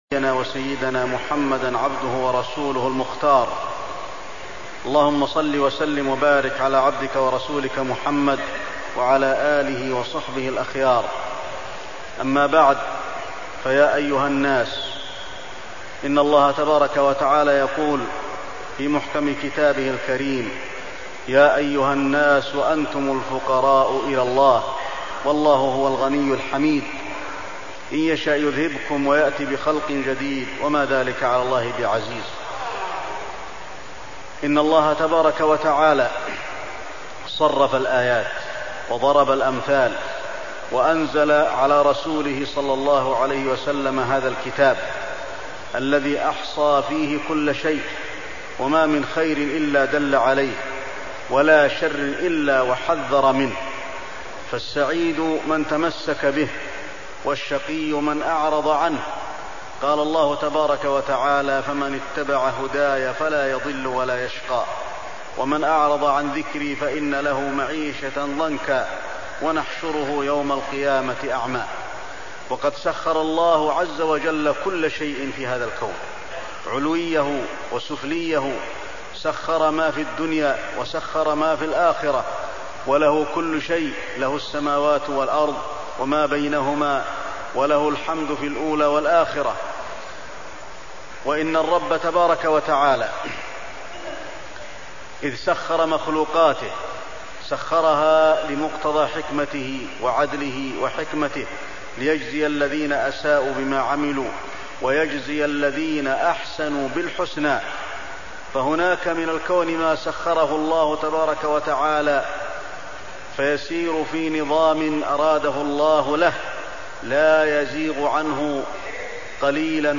خطبة الخسوف المدينة - الشيخ علي الحذيفي
تاريخ النشر ١٥ ذو القعدة ١٤١٦ هـ المكان: المسجد النبوي الشيخ: فضيلة الشيخ د. علي بن عبدالرحمن الحذيفي فضيلة الشيخ د. علي بن عبدالرحمن الحذيفي خطبة الخسوف المدينة - الشيخ علي الحذيفي